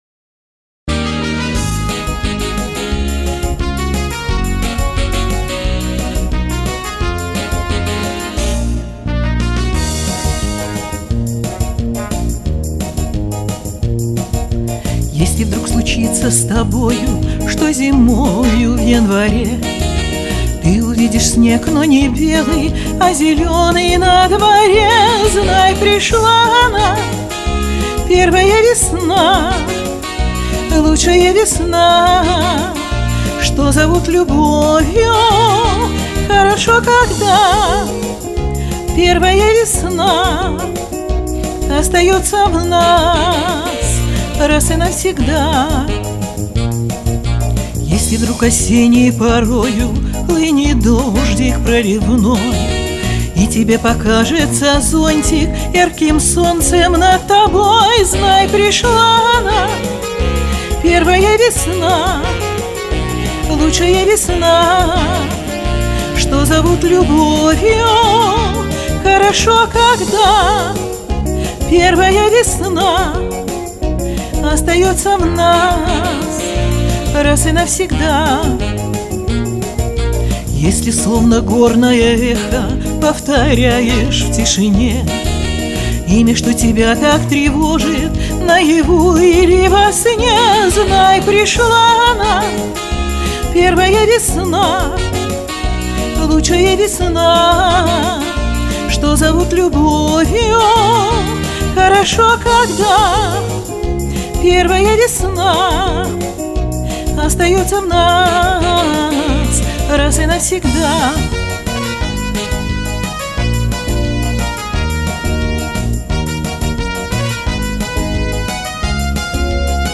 песенки 60-70-х,сделанные под твист.